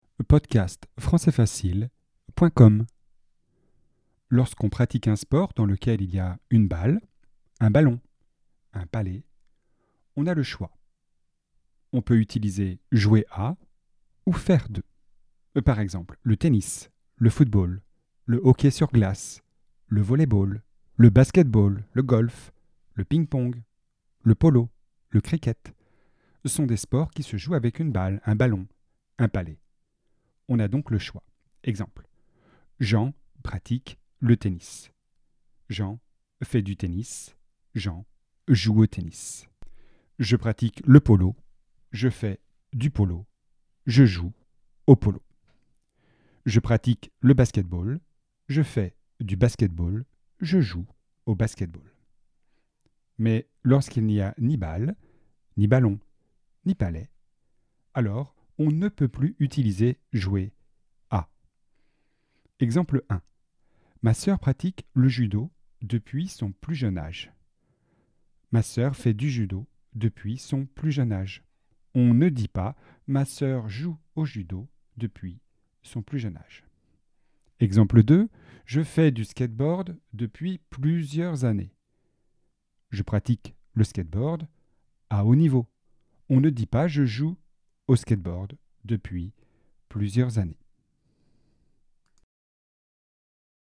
Leçon de grammaire, niveau débutant (A2), sur le thème du sport.